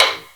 taiko-normal-hitwhistle.ogg